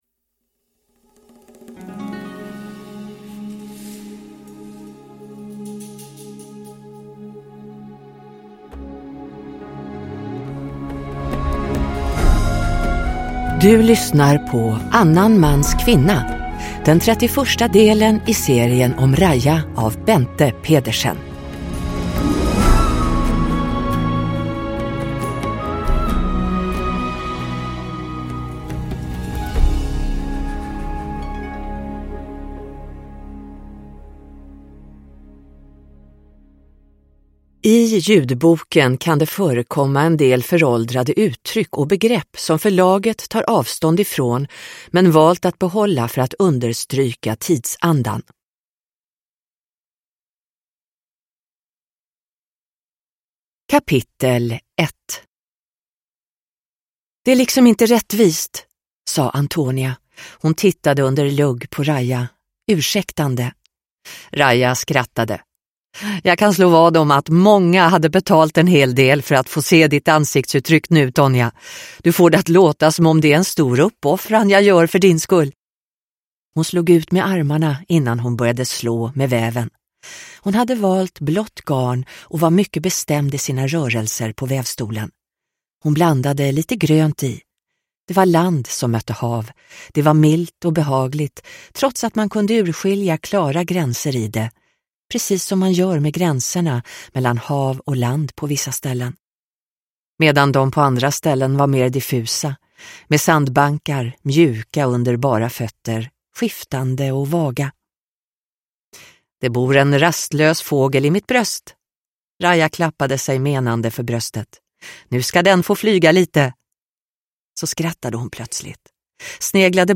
Annan mans kvinna – Ljudbok – Laddas ner